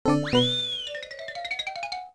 Index of /phonetones/unzipped/LG/KE260/03 Message Tones